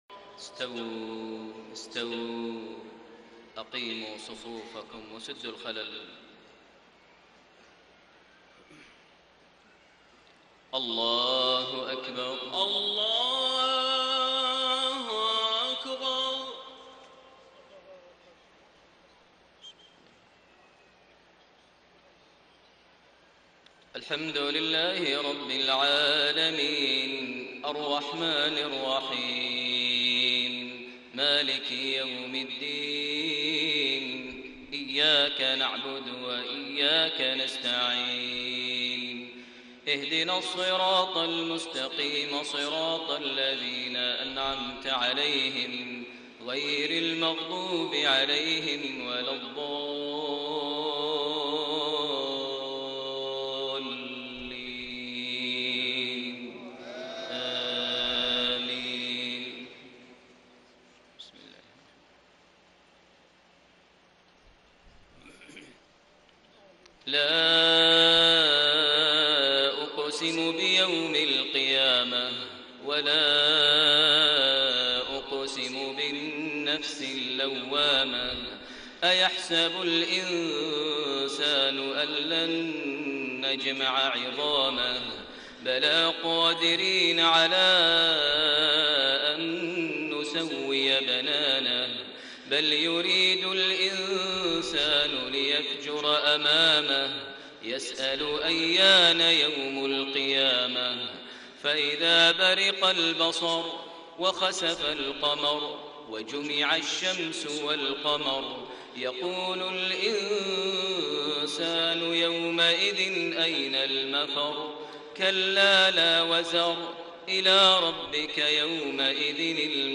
صلاة المغرب 7 شعبان 1433هـ سورة القيامة > 1433 هـ > الفروض - تلاوات ماهر المعيقلي